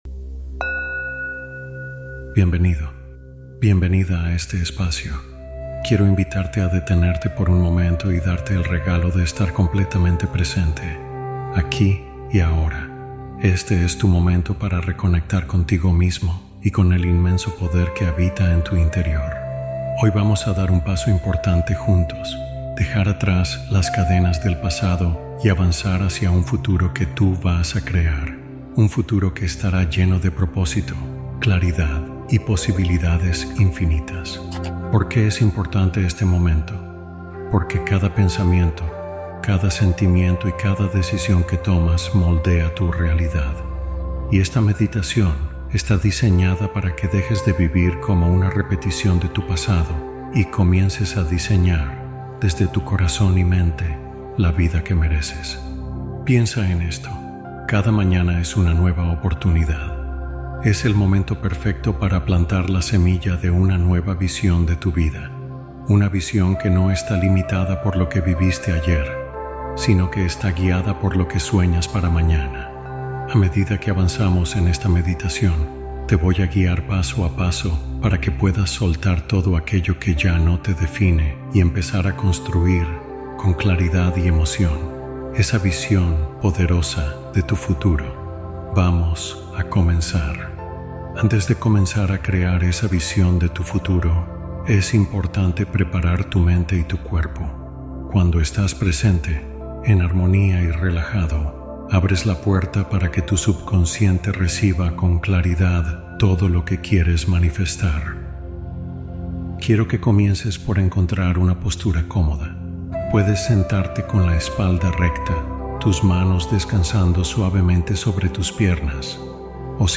Nueva Meditación Matutina Para Crear una Nueva Vida